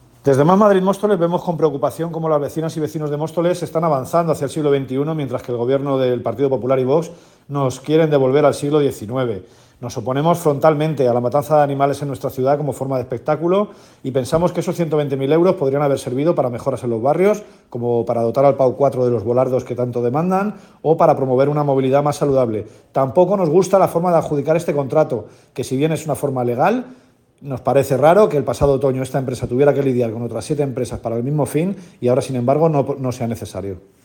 declaraciones-emilio-delgado-toros.mp3